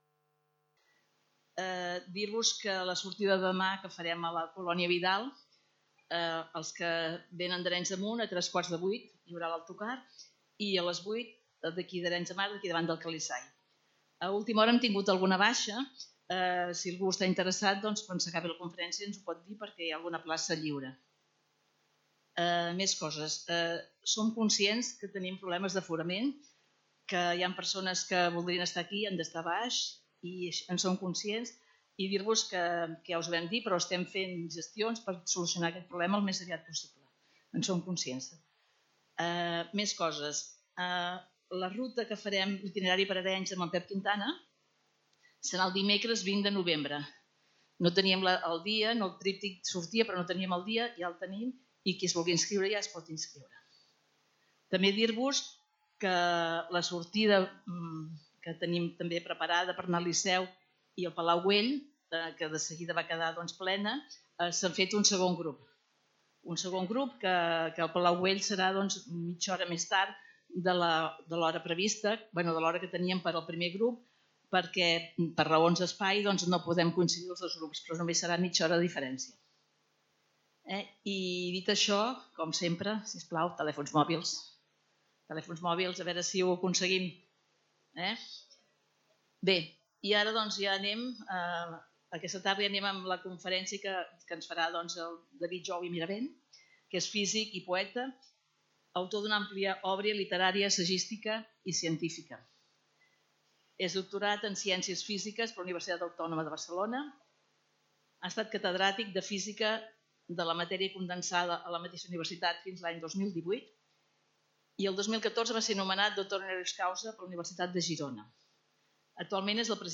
Conferenciant: David Jou Mirabent Lloc: Centre Cultural Calisay
Categoria: Conferències